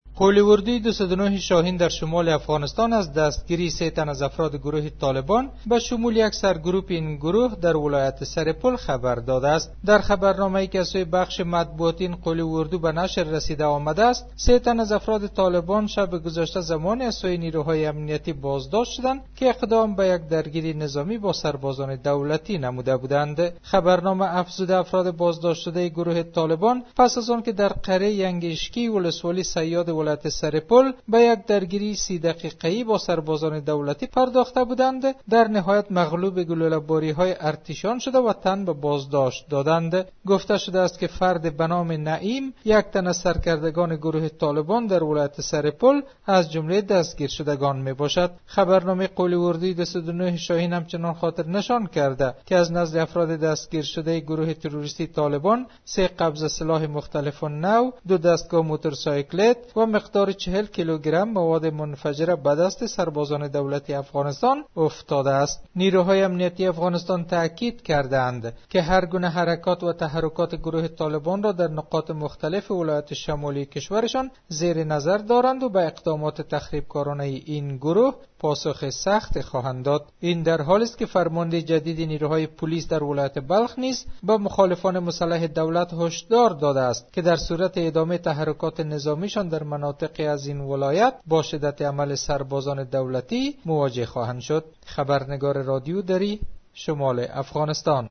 نیروهای امنیتی افغانستان در جریان درگیری با اعضای گروه طالبان در ولایت سرپل سه تن از جمله یک فرمانده محلی طالبان را بازداشت کردند گزارش تکمیلی از همکارمان...